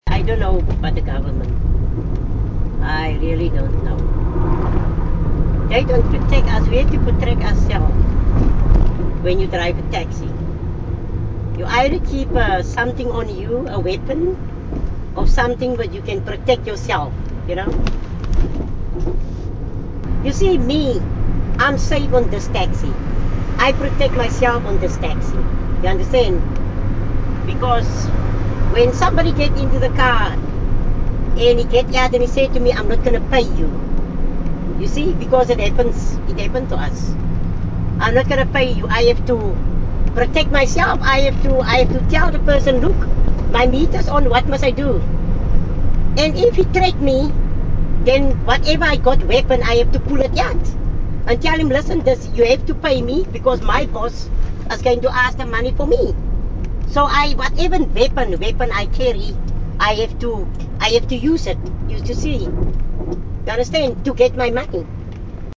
Taxi tales - Cape Town taxi drivers speak about feminism
A female taxi driver, however, was less impressed with Zille.